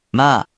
In romaji, 「ま」 is transliterated as「ma」which sounds like 「mahh」which sounds like the first or last syllable of the ma in 「mama」